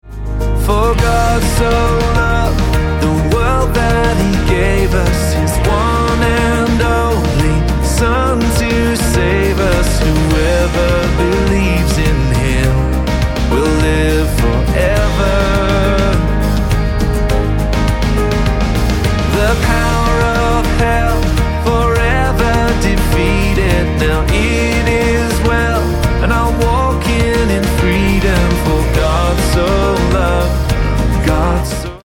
Eb